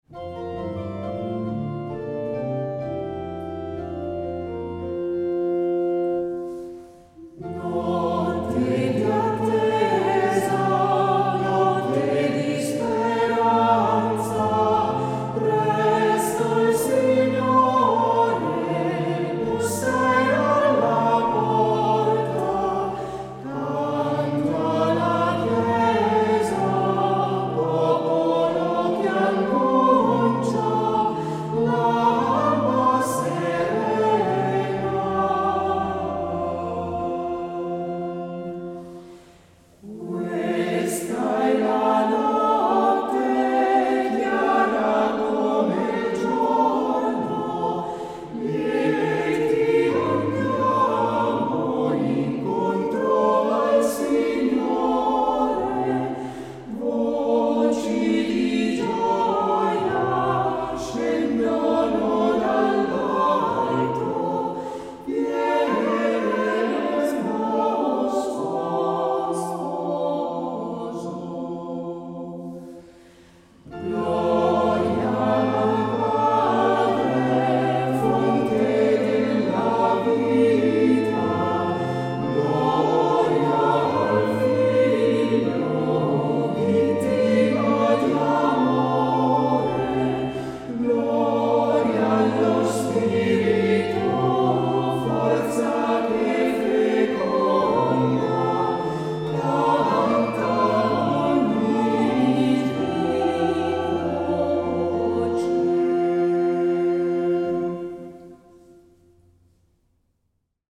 Versione a 3 voci
Notte-di-attesa-3-voci-Rusconi.mp3